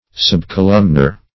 Search Result for " subcolumnar" : The Collaborative International Dictionary of English v.0.48: Subcolumnar \Sub`co*lum"nar\, a. (Geol.) Having an imperfect or interrupted columnar structure.
subcolumnar.mp3